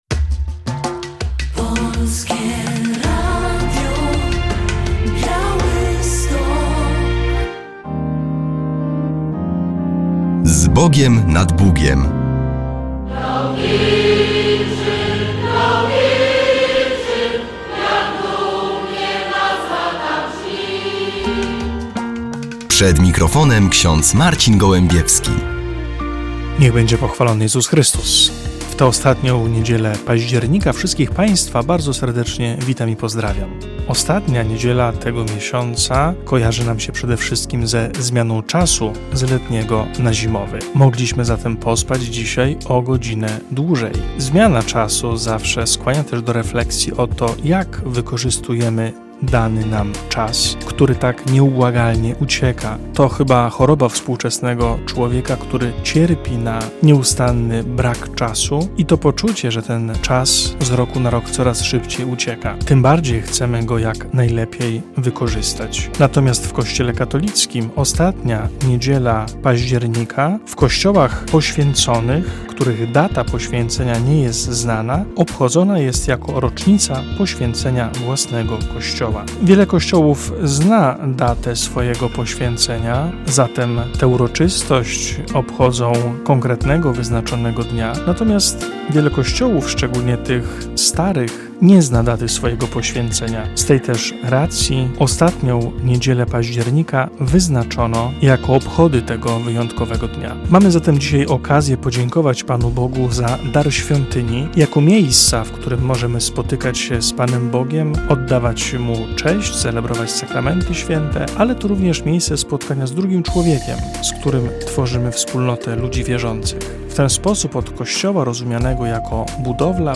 W audycji relacja z poświęcenia kamienia węgielnego pod budowę Centrum Charytatywno-Opiekuńczego Caritas Diecezji Drohiczyńskiej w Bielsku Podlaskim.